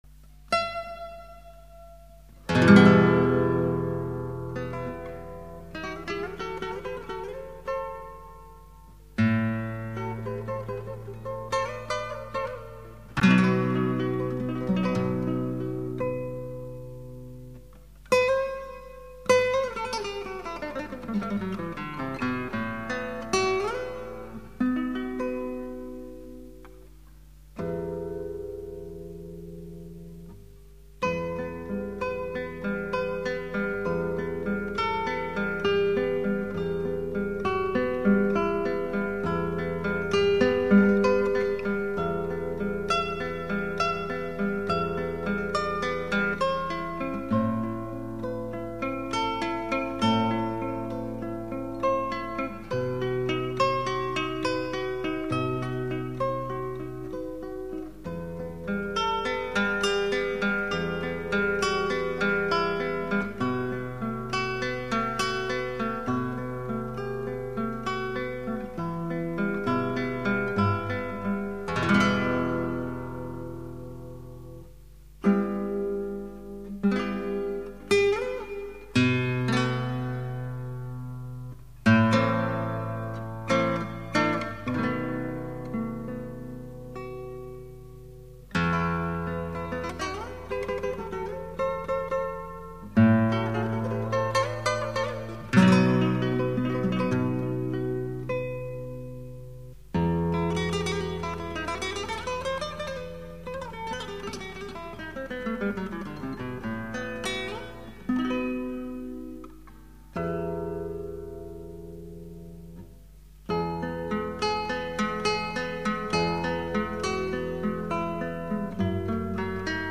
0123-吉他名曲爱的罗曼丝.mp3